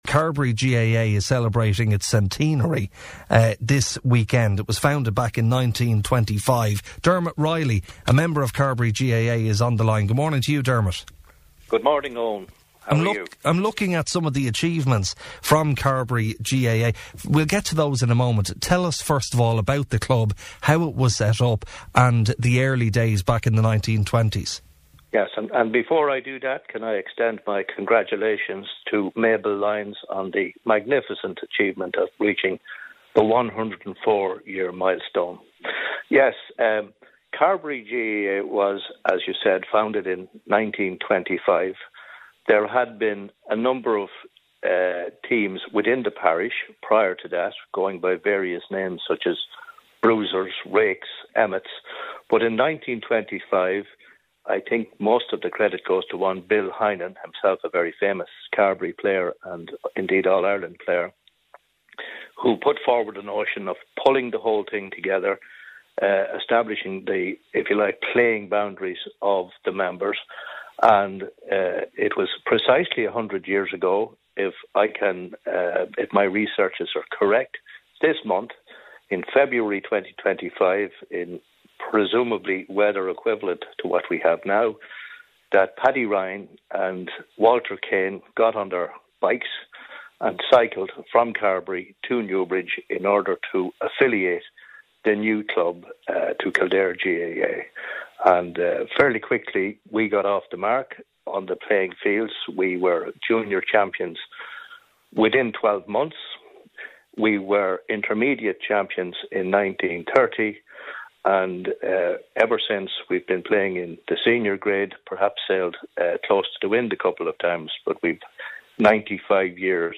member of the club, joined us on this morning's Kildare Today show to chat about the early days of the club